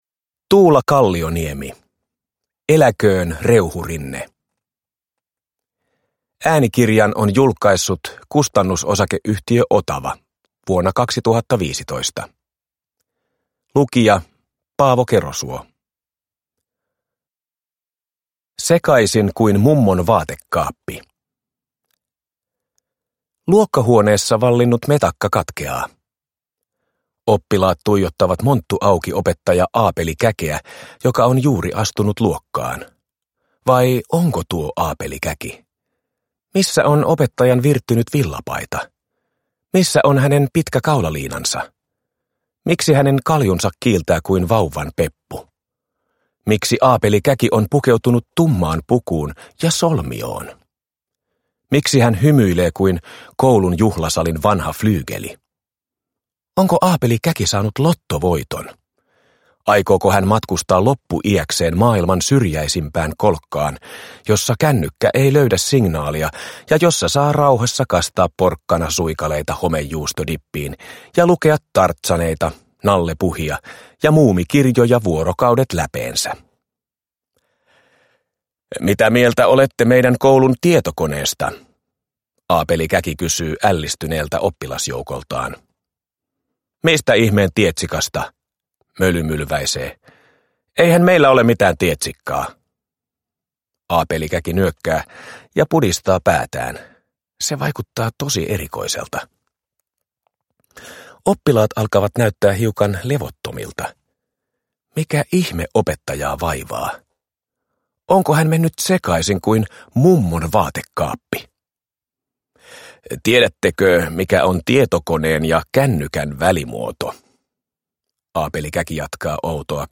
Eläköön Reuhurinne! – Ljudbok – Laddas ner